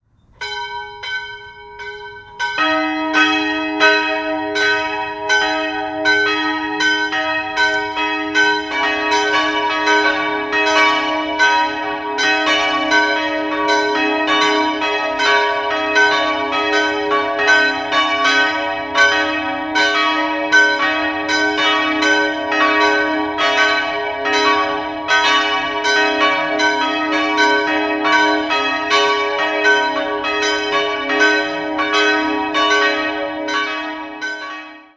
3-stimmiges Gloria-Geläute: cis''-dis''-fis'' Die beiden großen Glocken wurden 1971 von Rudolf Perner in Passau gegossen, die kleine stammt von Johann Gordian Schelchshorn (Regensburg) aus dem Jahr 1695.